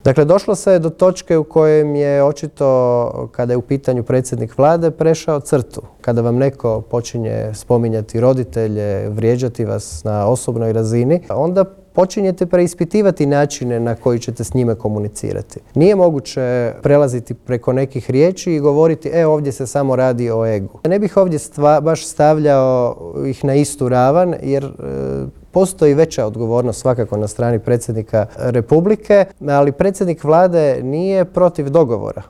Otkrio nam je u Intervjuu tjedna Media servisa uoči održavanja sjednice Odbora za gospodarstvo upravo na temu prodaje plina višestruko ispod tržišne cijene.